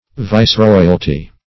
Viceroyalty \Vice*roy"al*ty\, n. The dignity, office, or jurisdiction of a viceroy.